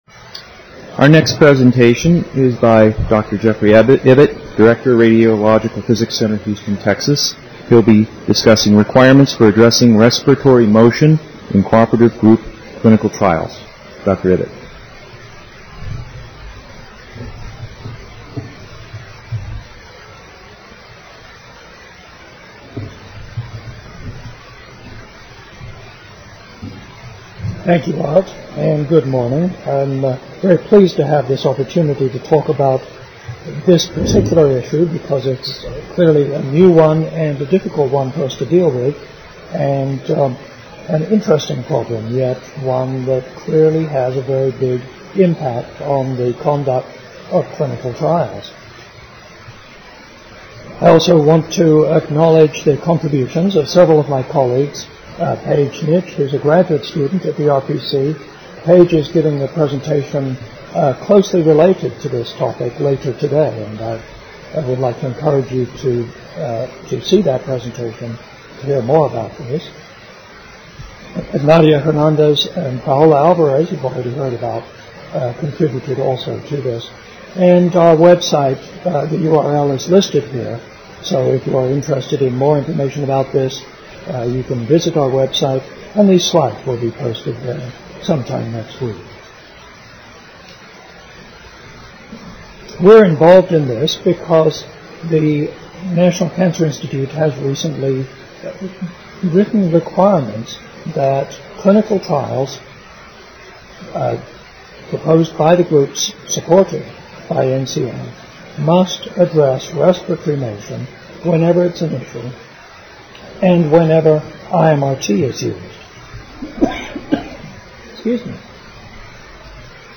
49th AAPM Annual Meeting - Session: CE-Therapy: Quality Assurance for Advanced RT Technologies: The Challenge for Clinical RT Trials